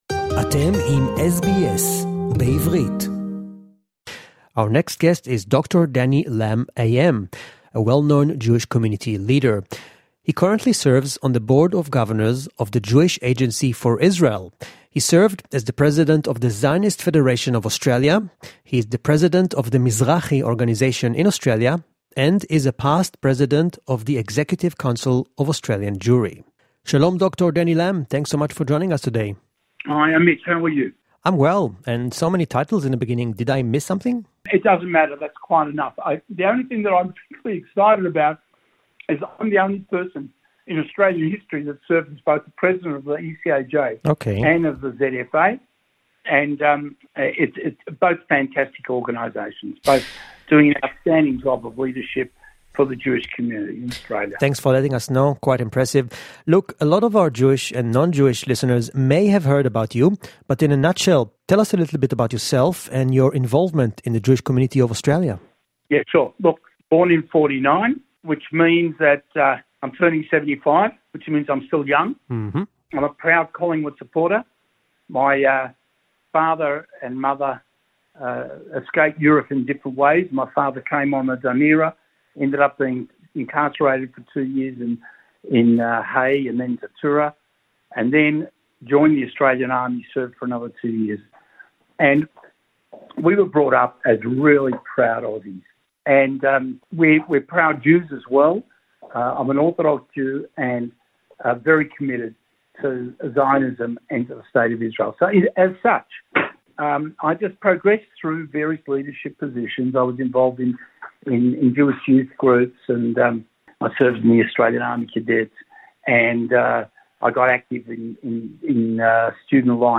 English interview.